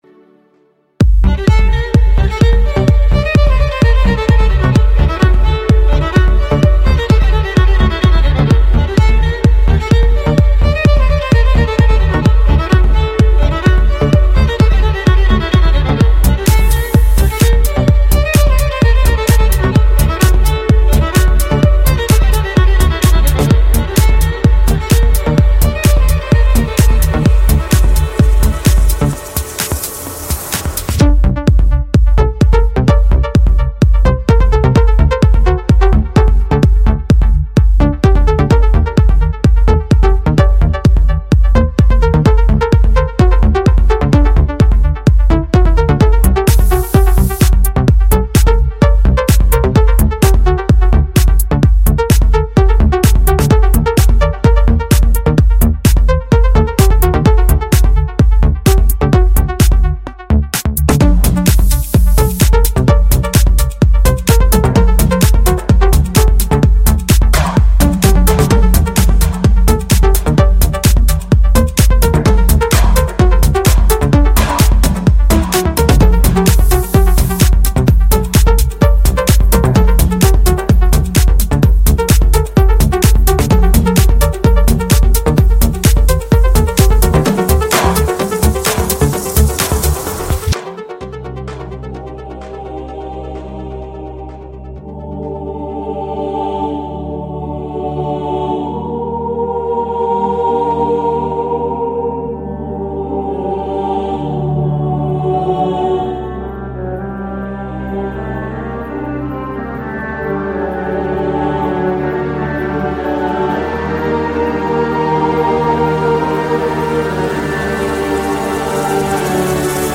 Скрипка_Классика_в_современной_обработке
Skripka_Klassika_v_sovremennoq_obrabotke.mp3